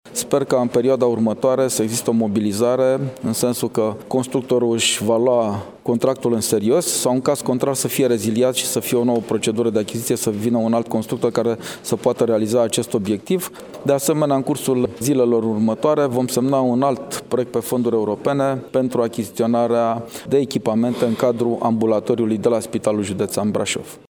Președintele Consiliului Județean Brașov, Adrian Veștea, a declarat astăzi ca si-ar dori ca la Unitatea de Primiri Urgențe de la Spitalul Judetean Brasov constructorul să mărească ritmul de lucru.